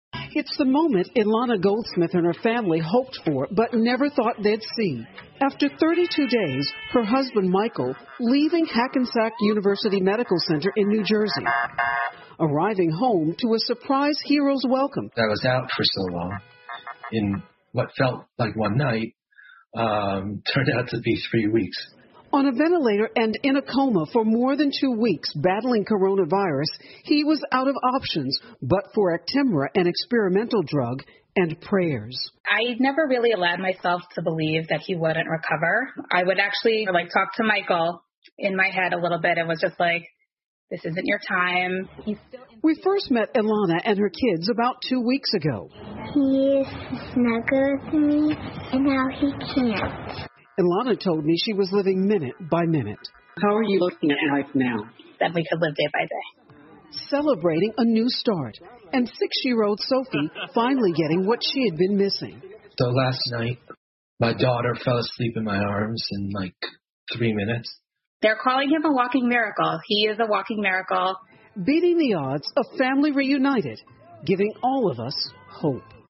NBC晚间新闻 美国一患者使用新药后出院 听力文件下载—在线英语听力室